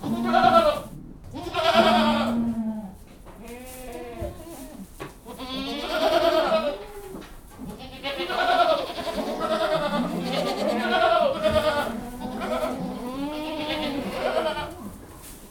goats.ogg